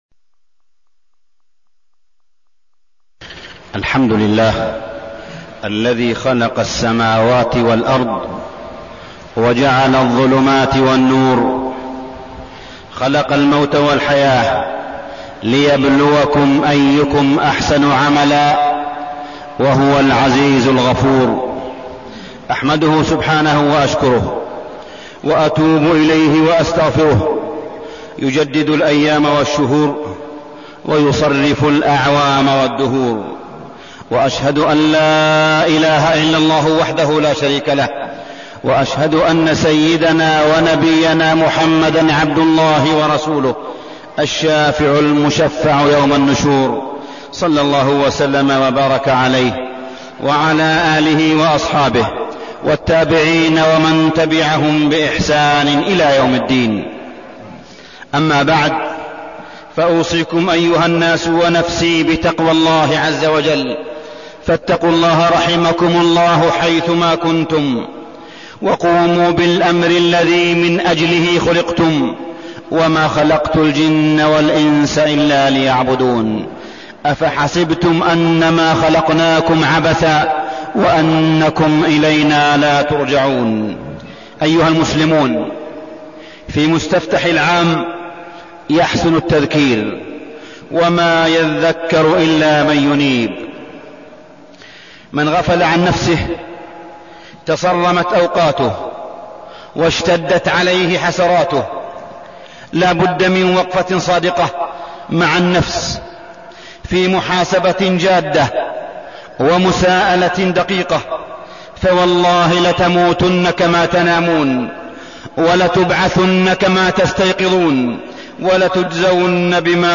تاريخ النشر ١٤ محرم ١٤١٧ هـ المكان: المسجد الحرام الشيخ: معالي الشيخ أ.د. صالح بن عبدالله بن حميد معالي الشيخ أ.د. صالح بن عبدالله بن حميد محاسبة النفس